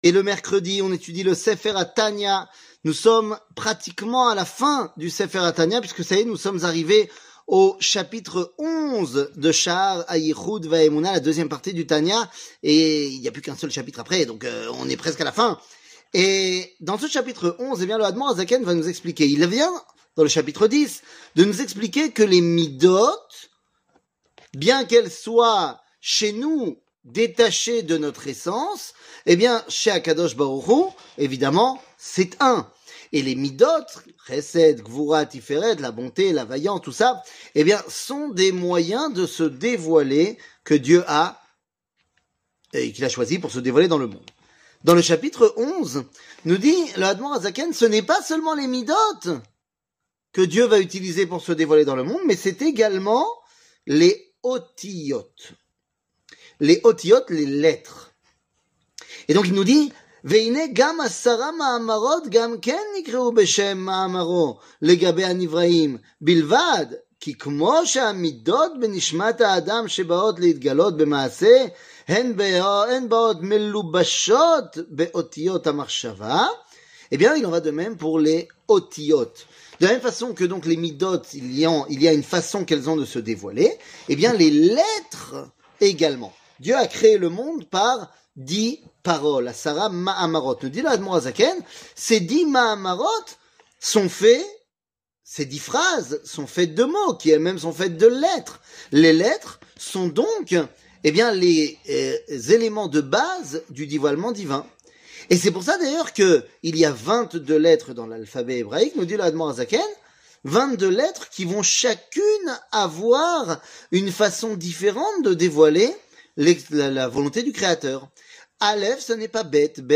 Tania, 65, Chaar Ayihoud Veaemouna, 11 00:04:42 Tania, 65, Chaar Ayihoud Veaemouna, 11 שיעור מ 25 אוקטובר 2023 04MIN הורדה בקובץ אודיו MP3 (4.3 Mo) הורדה בקובץ וידאו MP4 (7.84 Mo) TAGS : שיעורים קצרים